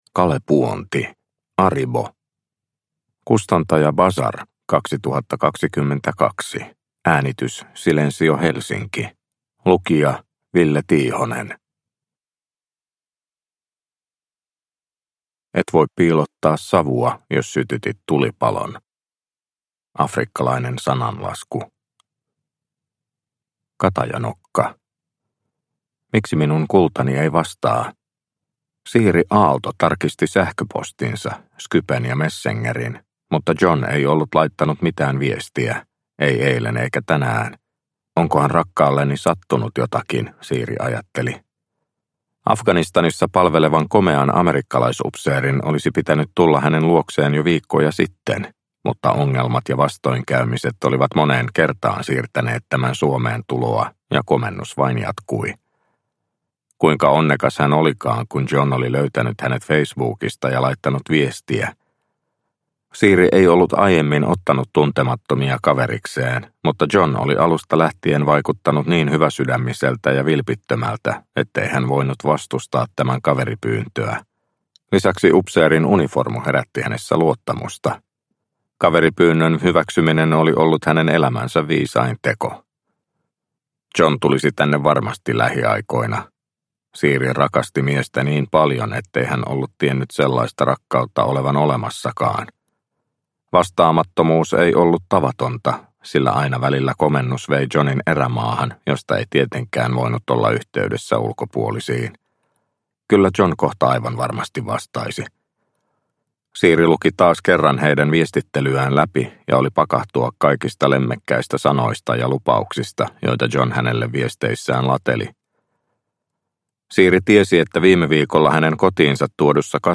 Aribo – Ljudbok – Laddas ner